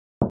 Knock.mp3